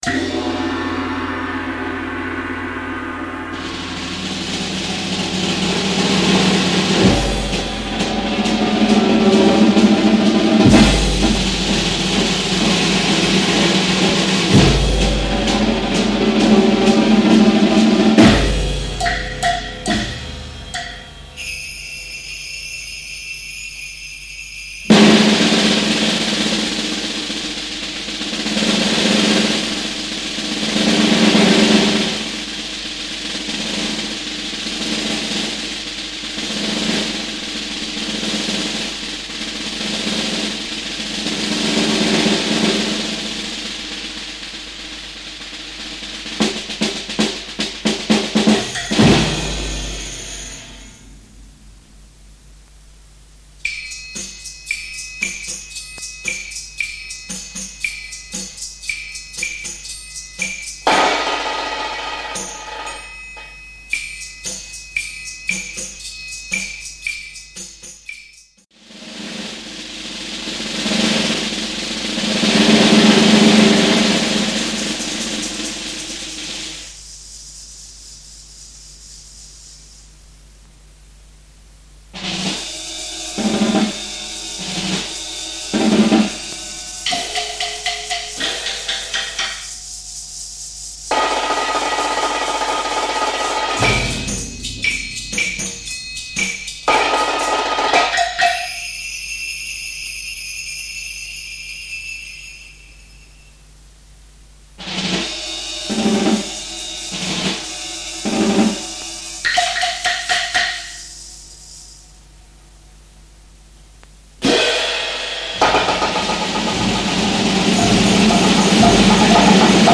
Genre Concert & Contest